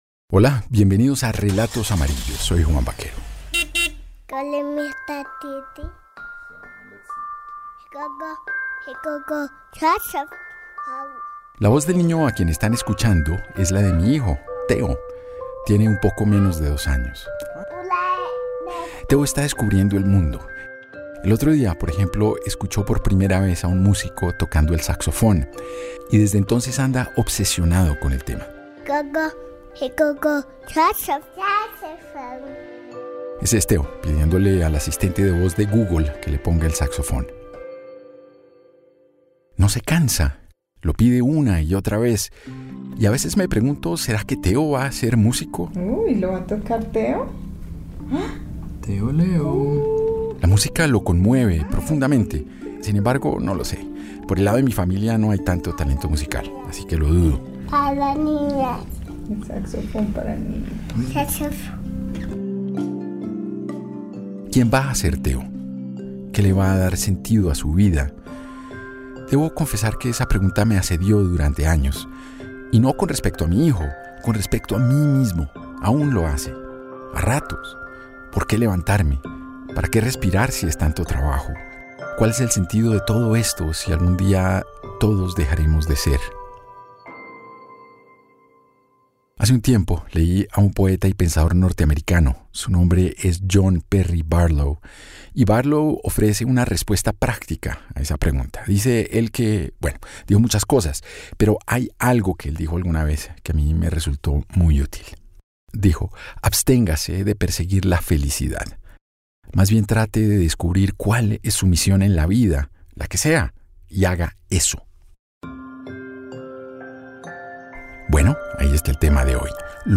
Tres taxistas narran los momentos en que su oficio casi los hace alcanzar la fama.